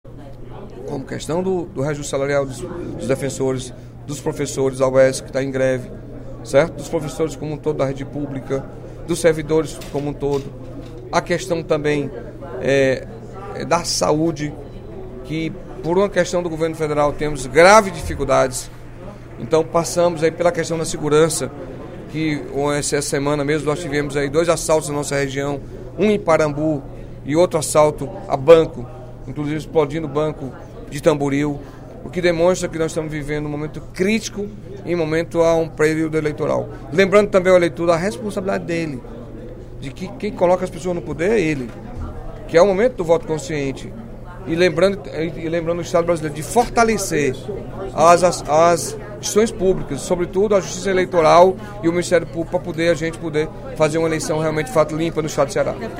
O deputado Carlos Felipe (PCdoB) destacou, nesta terça-feira (02/08), durante o primeiro expediente da sessão plenária, a responsabilidade da Assembleia Legislativa em continuar “tocando” os trabalhos, durante o processo eleitoral, em razão de muitas crises em diversas áreas do Estado.